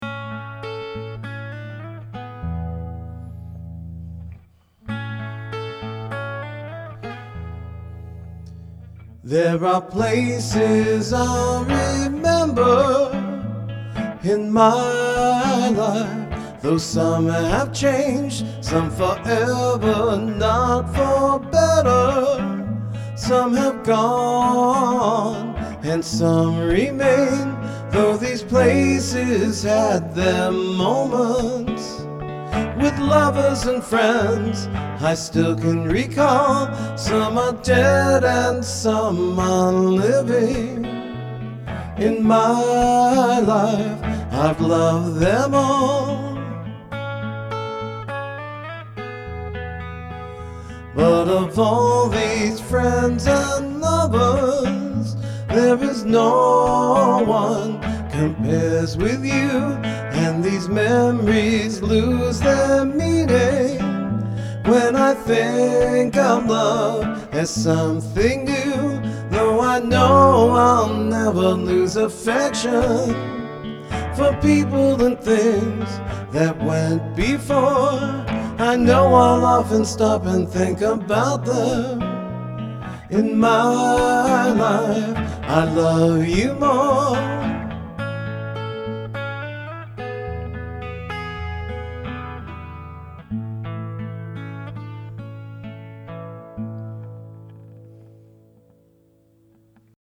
Here are a few example clips I recorded direct into my DAW: